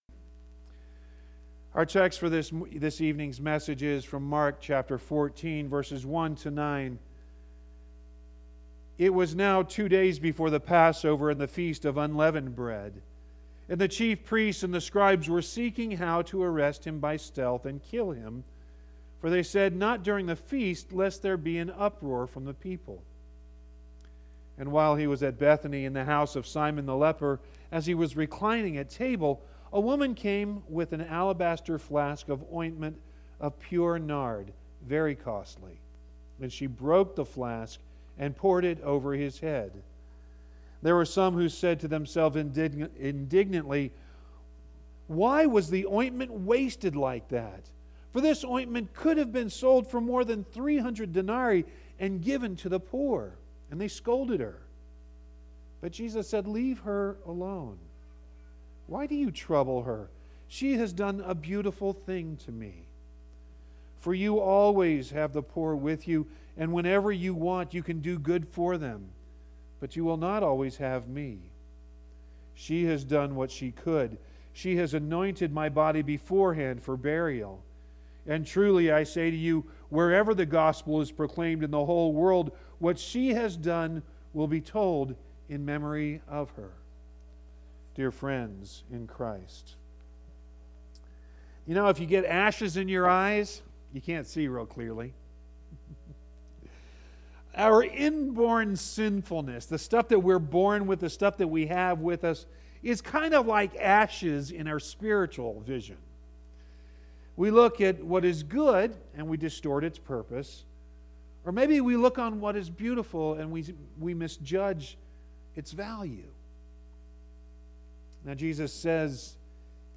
Ash Wednesday 02.26.20